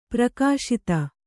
♪ prakāśita